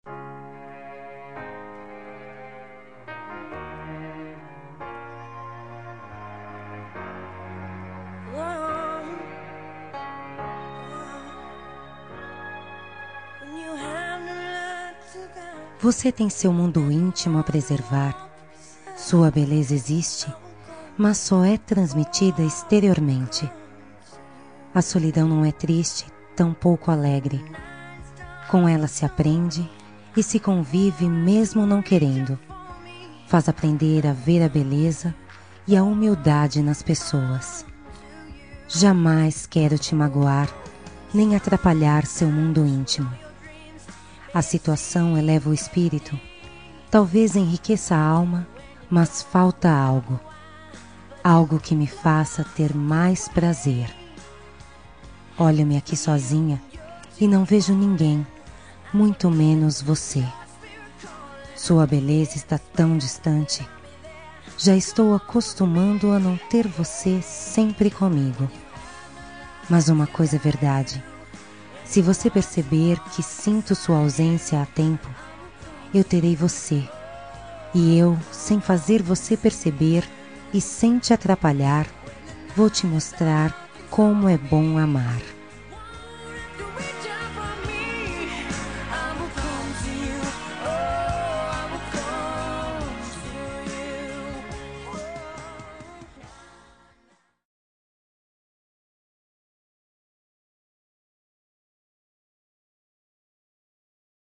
Toque para Não Terminar – Voz Feminina – Cód: 464 – Me dê Atenção